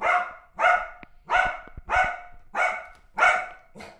dog-dataset
puppy_0030.wav